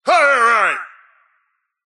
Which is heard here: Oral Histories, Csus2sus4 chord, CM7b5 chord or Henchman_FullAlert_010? Henchman_FullAlert_010